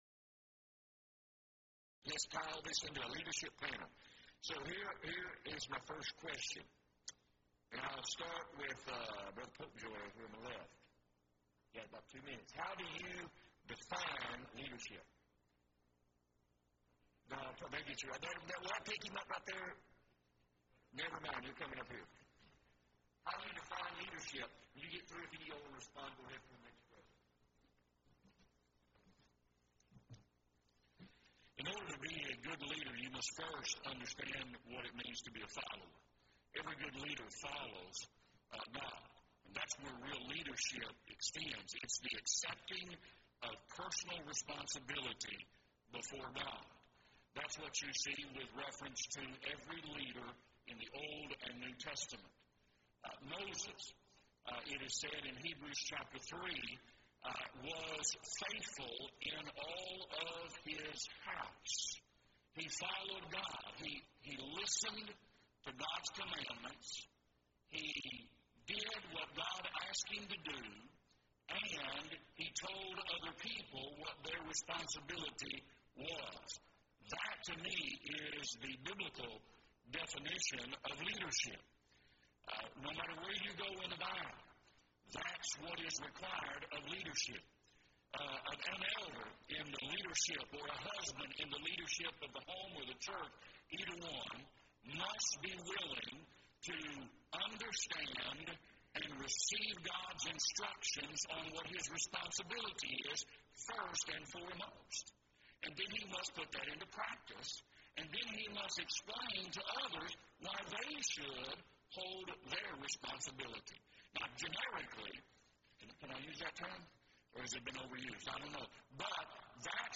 Event: 2nd Annual Young Men's Development Conference
lecture